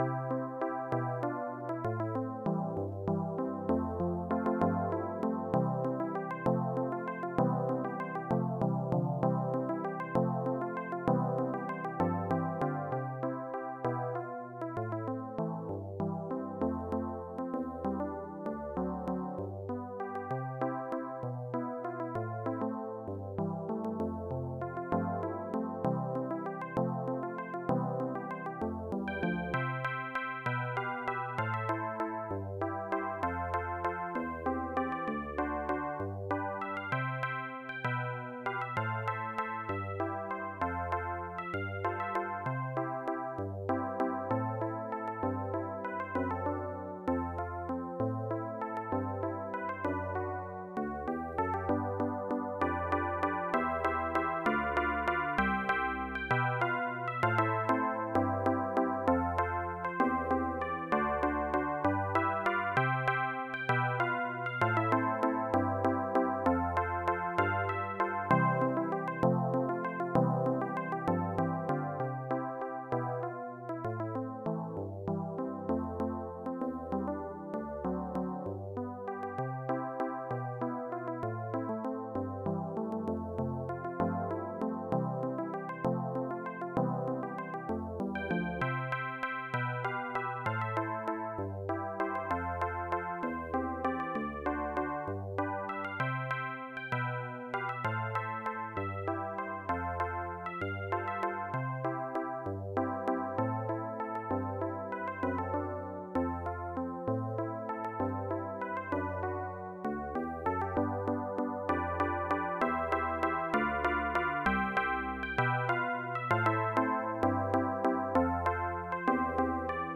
AdLib/Roland Song
PIANO.mp3